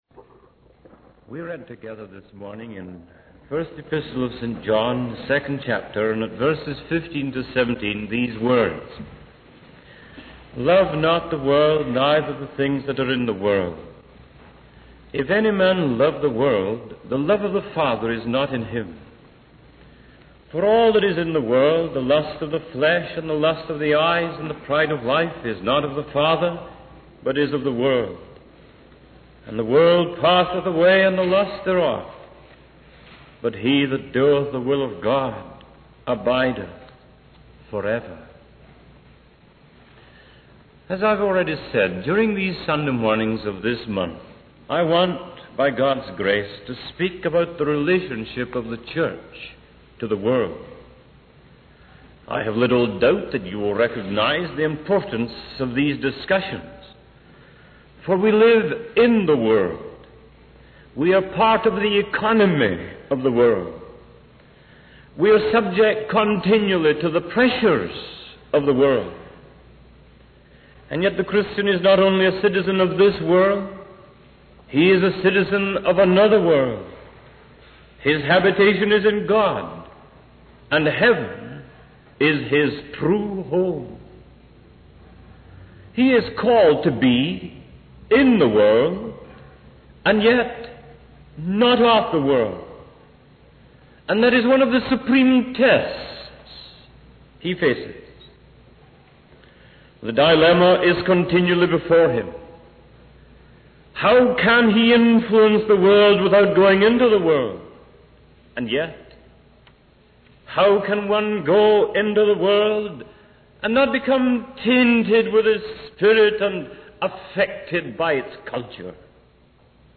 In this sermon, the preacher emphasizes the importance of being vigilant and not allowing our hearts to be consumed by worldly distractions such as excess, drunkenness, and the cares of life. He urges the listeners to pray always and strive to be worthy of escaping the trials and tribulations that will come.